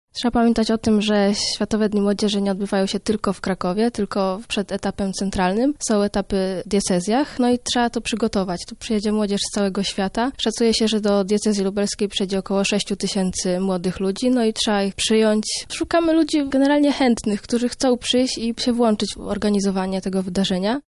wyjaśnia jedna z wolontariuszek Światowych Dni Młodzieży